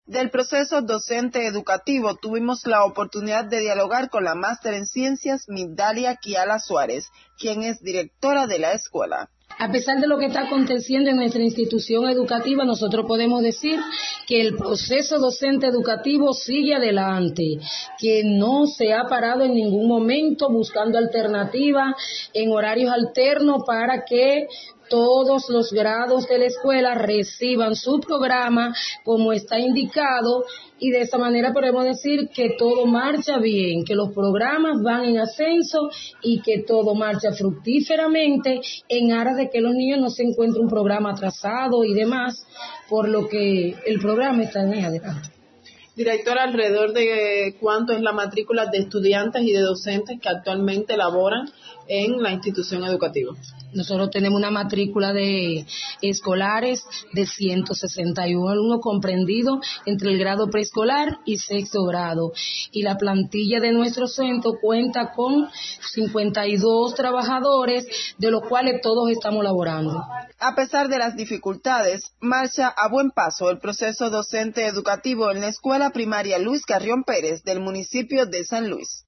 Allí se forman niños desde prescolar hasta el sexto grado. Sobre sus avances en el proceso docente educativo escuche más a través de este reporte.